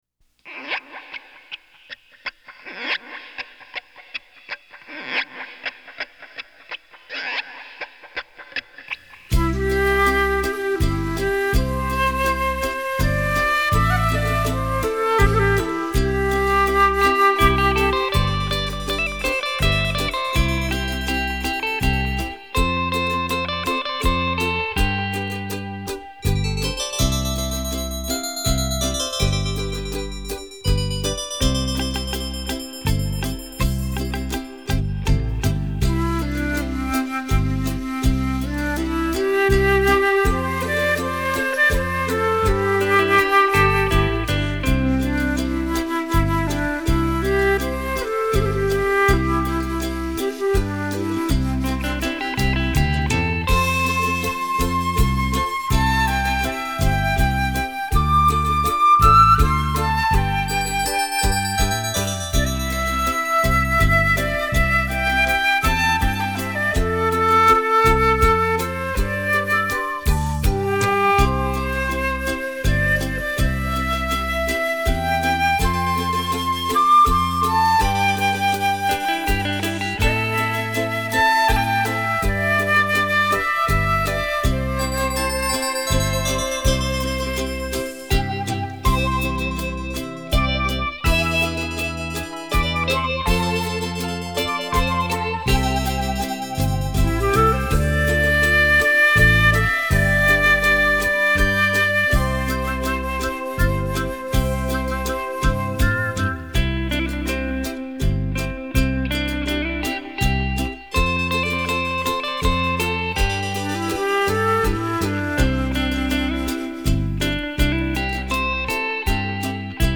还是喜欢的国语老歌旋律，谢谢分享